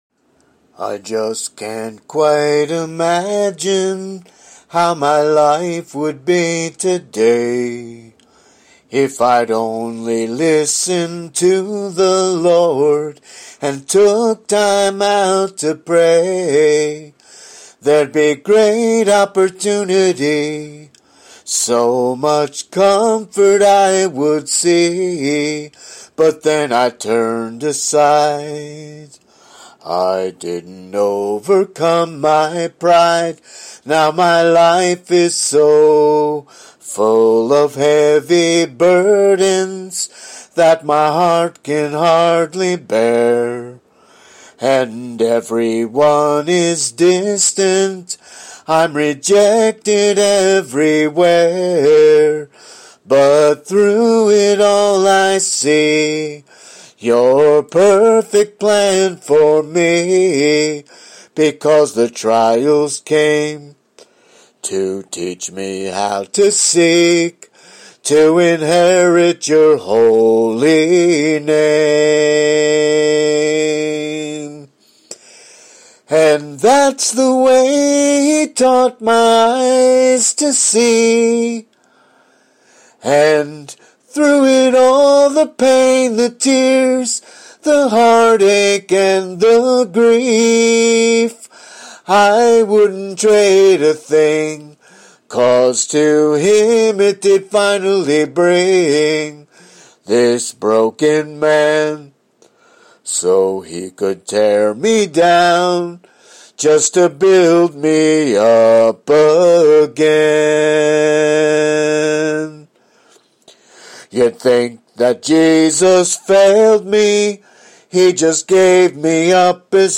most are “a cappella” (voice only)